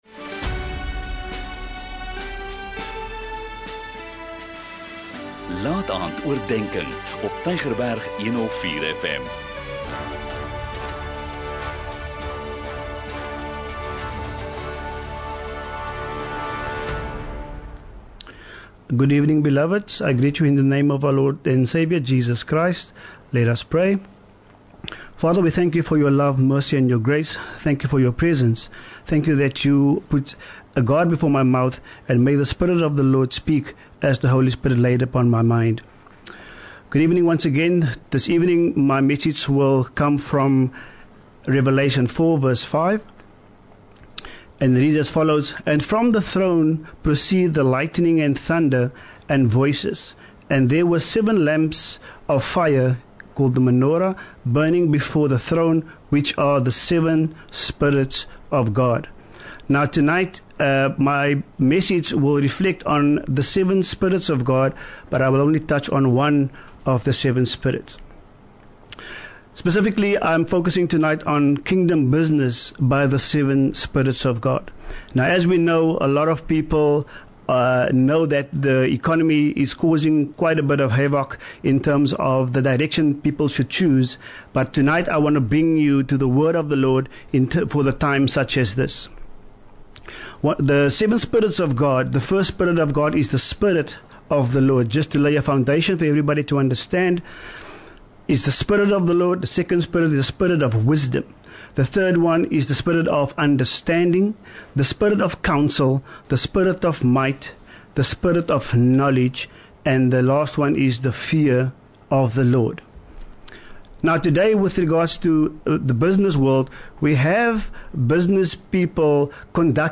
'n Kort bemoedigende boodskap, elke Sondagaand om 20:45, aangebied deur verskeie predikers.